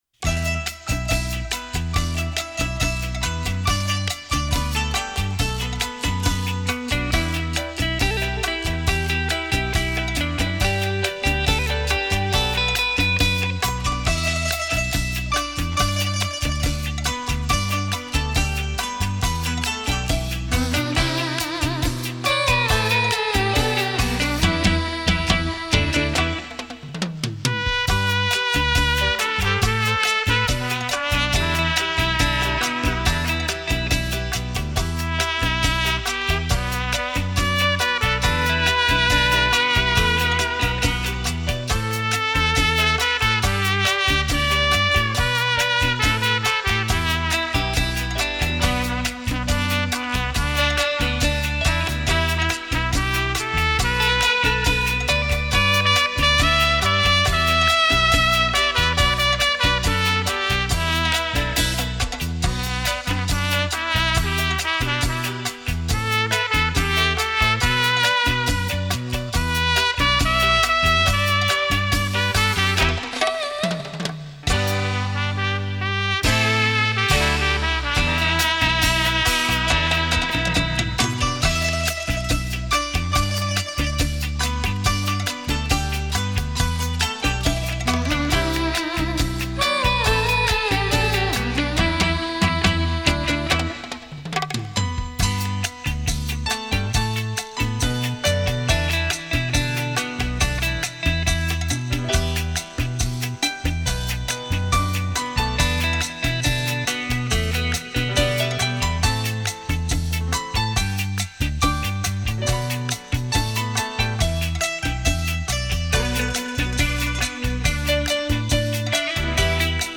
24bit 数位录音
专业级的演出，请您细细聆听
优美动听的旋律让人沉醉其中...